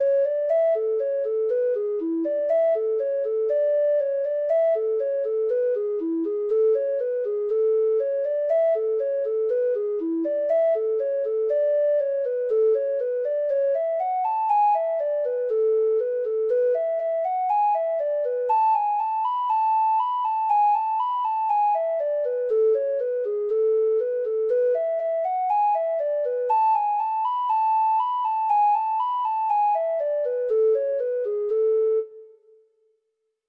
Free Sheet music for Treble Clef Instrument
Traditional Music of unknown author.
Reels